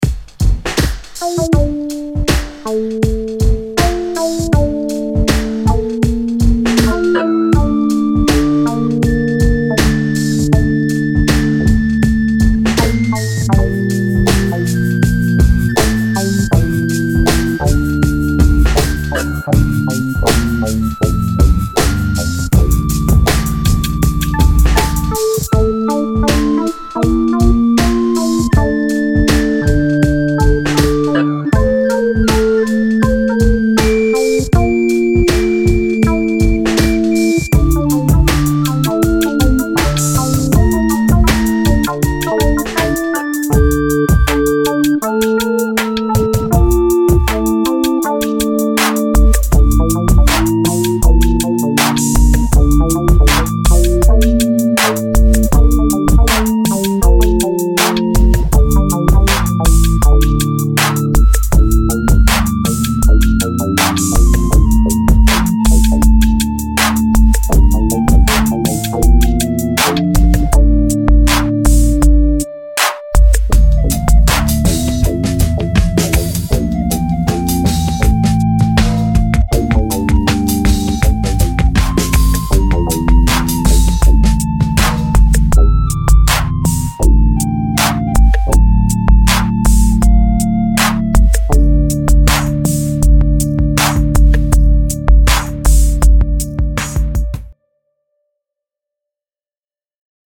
Posted in Classical, Dubstep, Other Comments Off on